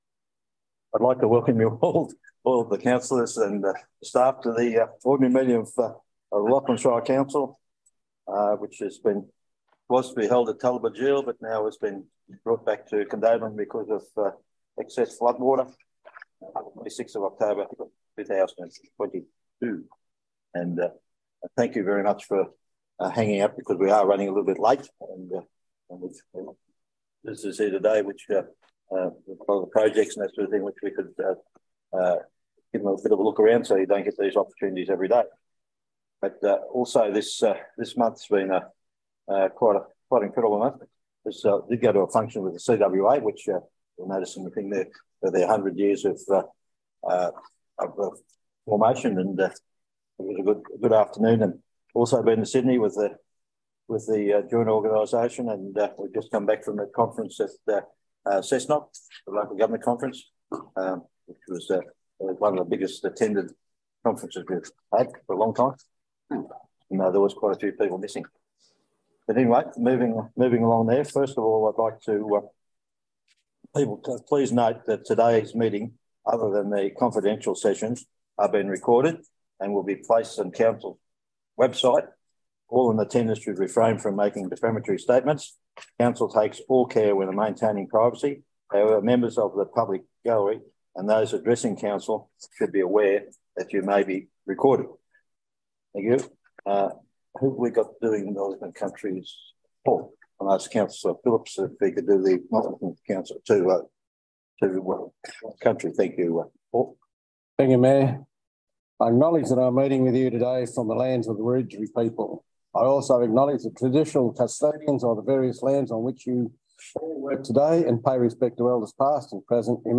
The 26 October meeting will be held in the Council Chambers and is open to the public